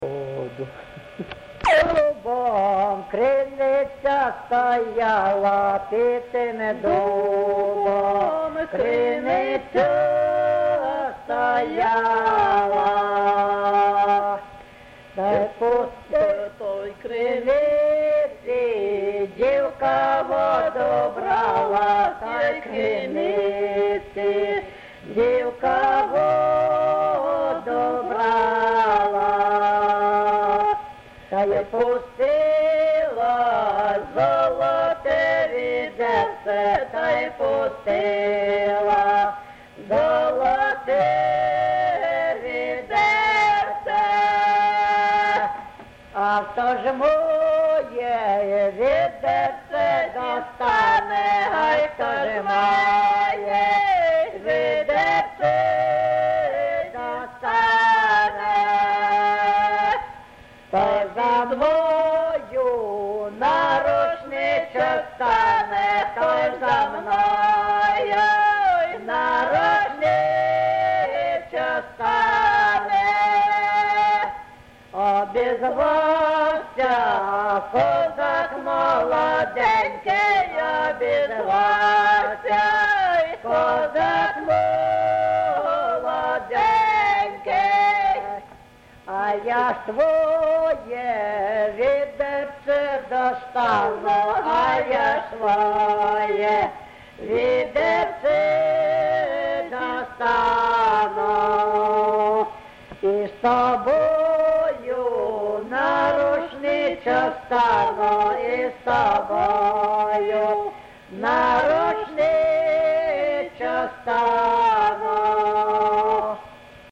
ЖанрПісні з особистого та родинного життя
Місце записум. Антрацит, Ровеньківський район, Луганська обл., Україна, Слобожанщина